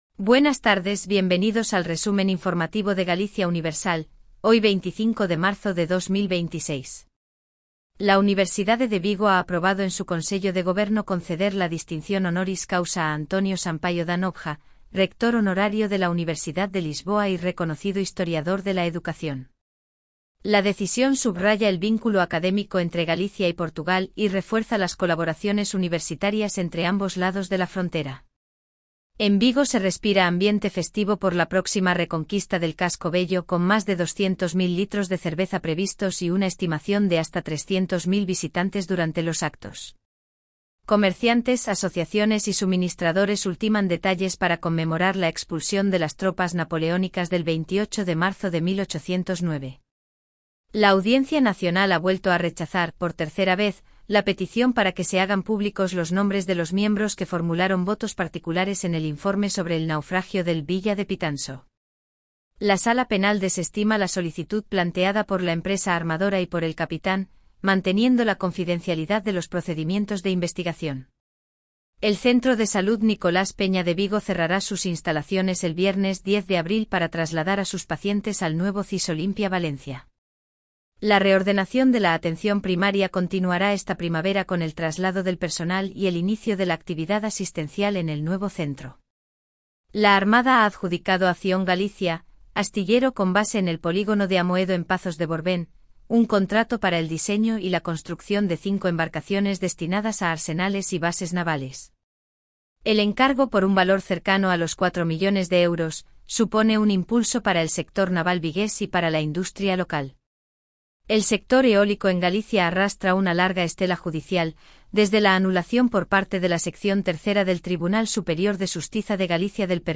🎙 PODCAST DIARIO
Resumo informativo de Galicia Universal